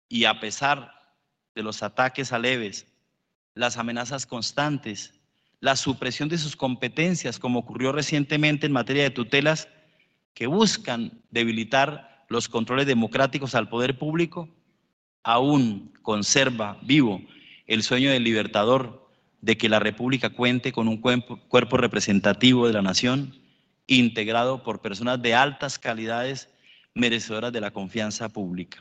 Presidente del Consejo de estado critica decreto presidencial
En el discurso en la instalación del Encuentro Regional de Justicia Abierta, Enfoque para una Justicia Preventiva, el presidente del Consejo de Estado recordó que el 5 de septiembre termina el periodo de Oswaldo Giraldo López tras 8 años de judicatura.